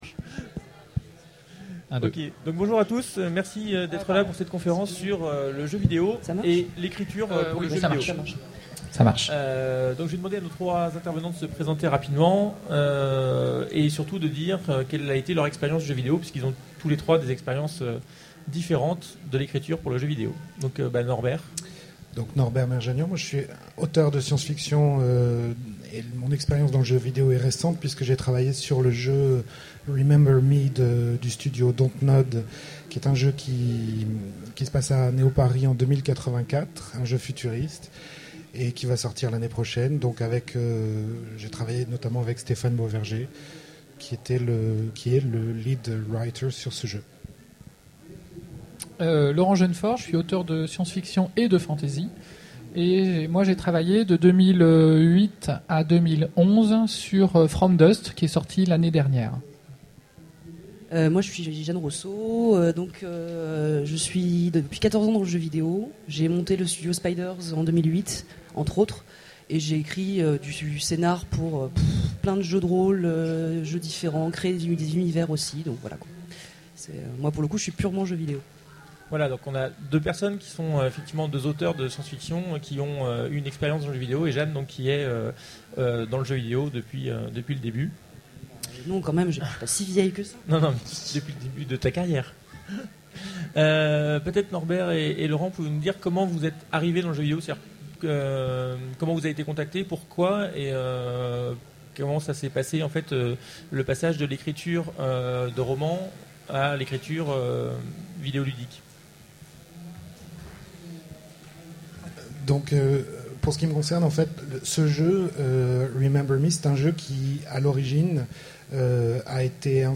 Utopiales 12 : Conférence Narration interactive